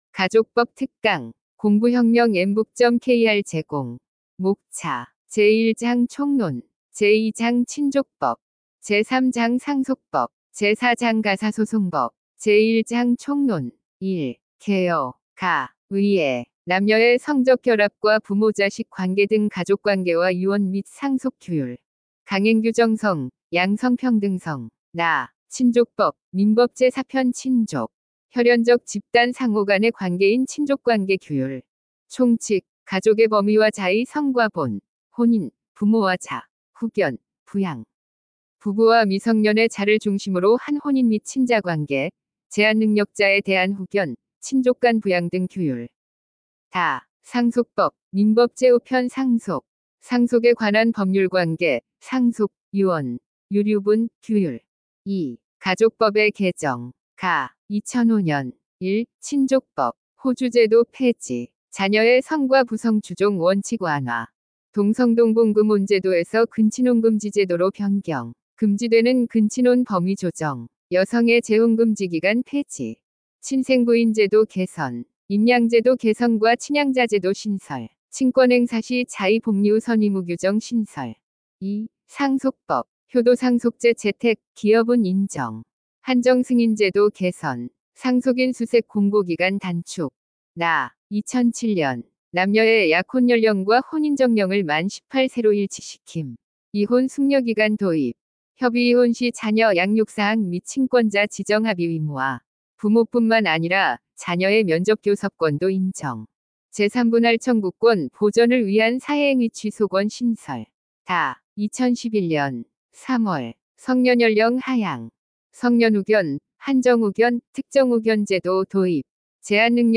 엠북학원은 우리나라 최초의 인공지능이 강의하는 사이버학원이며, 2025년 4월 28일 개원하였습니다.
가족법-특강-샘플.mp3